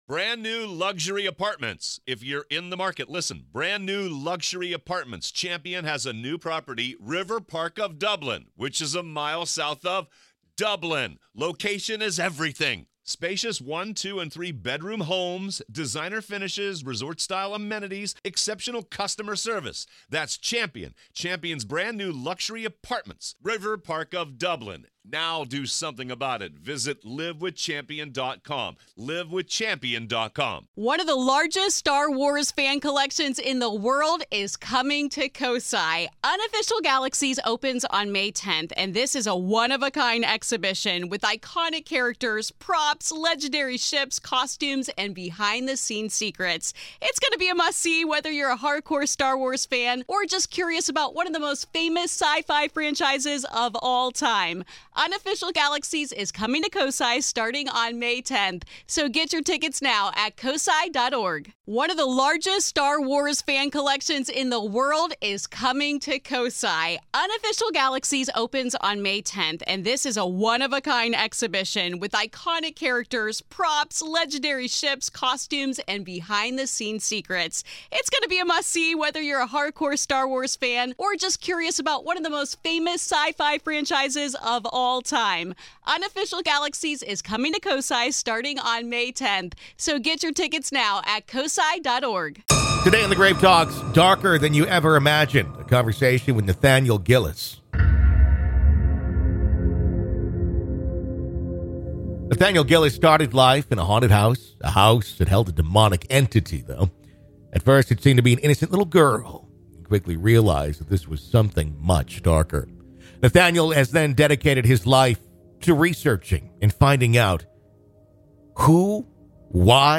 In part two of our interview, available only to Grave Keepers , we discuss: